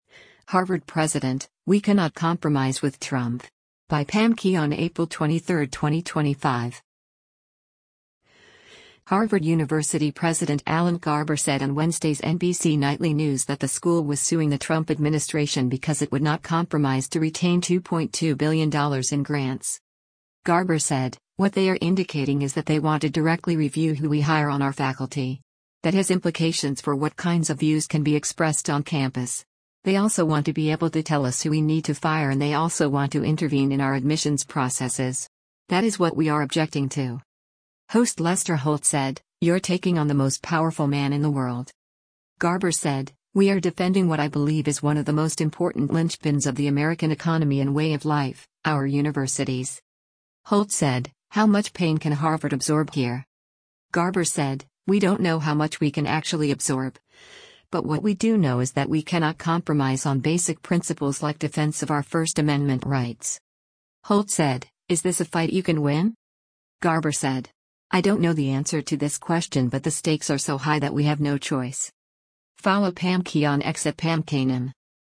Harvard University President Alan Garber said on Wednesday’s “NBC Nightly News” that the school was suing the Trump administration because it would not compromise to retain $2.2 billion in grants.
Host Lester Holt said, “You’re taking on the most powerful man in the world.”